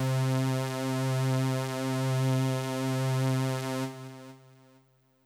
BAL Synth C2.wav